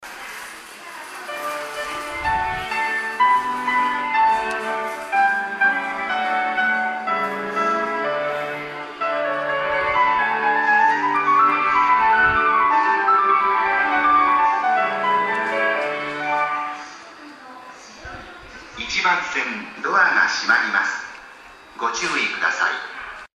１番線常磐線
発車メロディー  フルコーラスです。